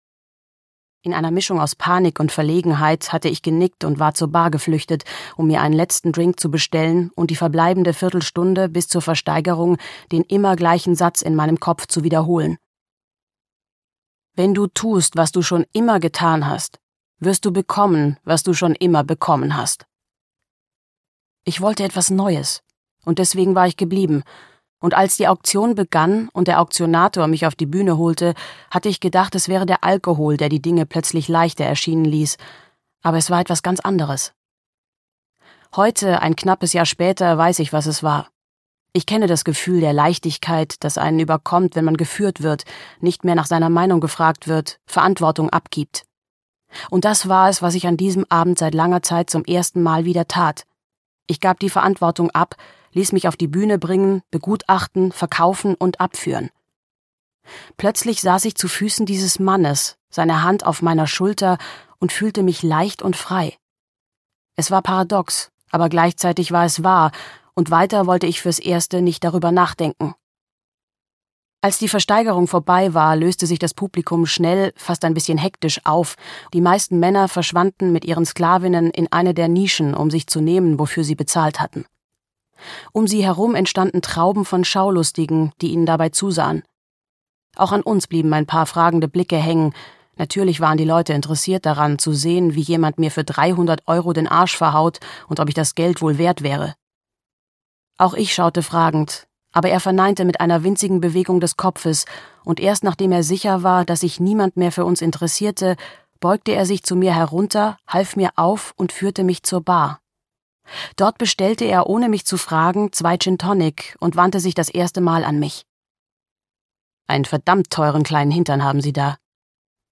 Hörbuch Stolz und Demut, Sophie Weiss.